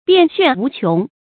變炫無窮 注音： ㄅㄧㄢˋ ㄒㄨㄢˋ ㄨˊ ㄑㄩㄥˊ 讀音讀法： 意思解釋： 變化多種多樣，沒有窮盡。極言變化之多。